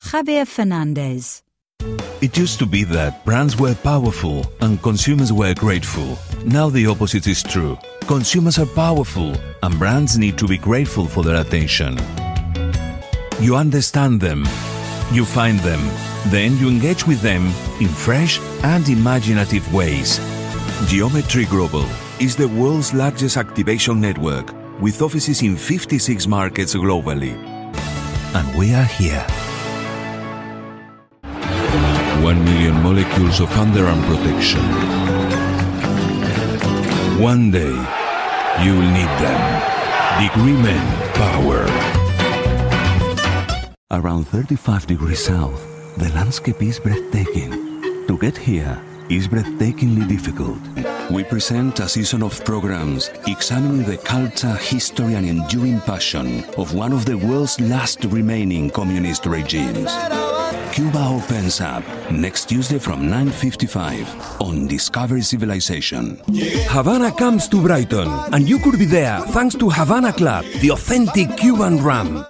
Age range: 40s - 60s
Showreel 0:00 / 0:00